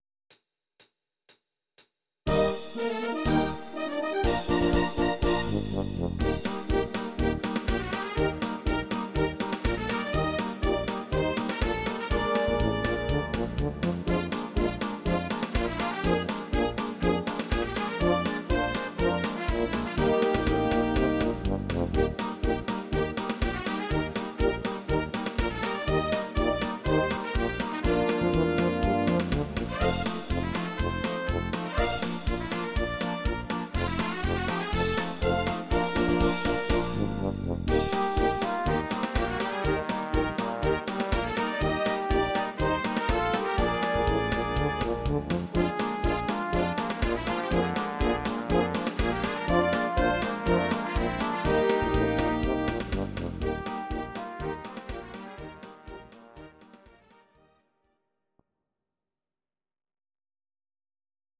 These are MP3 versions of our MIDI file catalogue.
Please note: no vocals and no karaoke included.
instumental Orchester